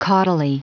Prononciation du mot caudally en anglais (fichier audio)
Prononciation du mot : caudally